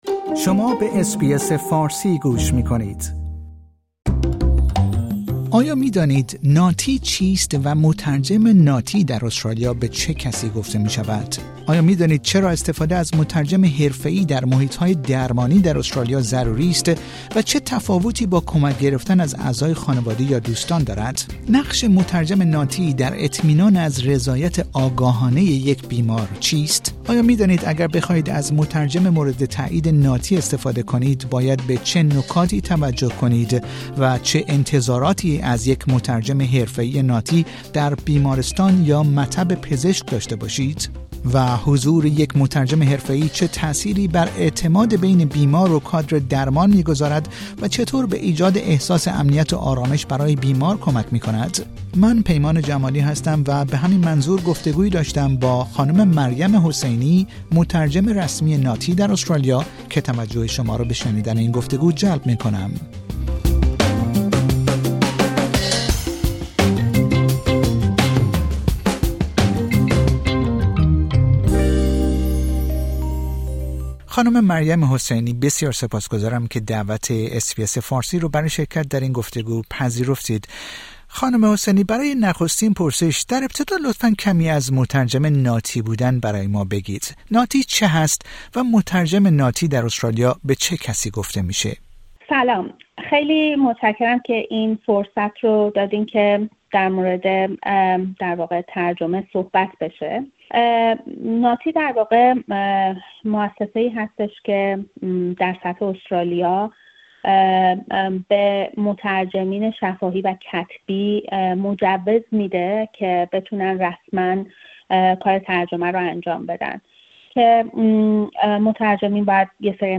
این گفتگو با حمایت ناتی انجام شده است.